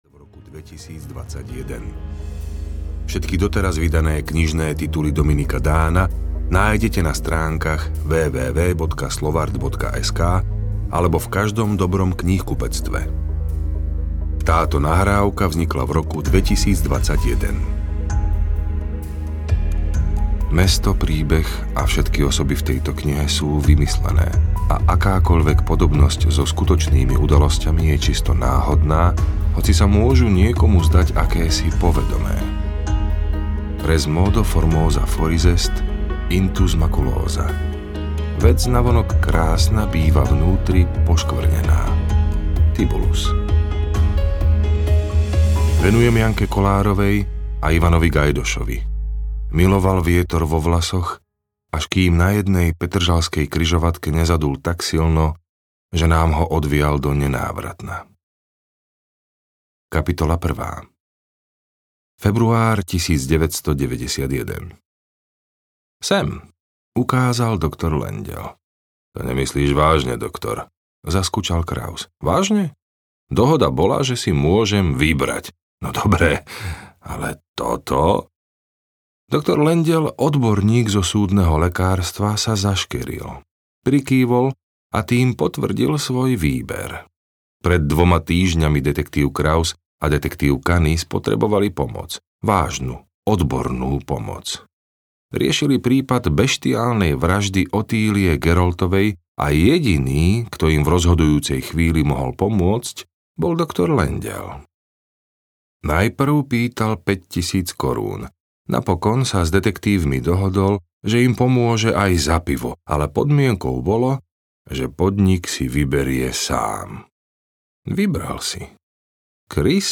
Bremeno minulosti audiokniha
Ukázka z knihy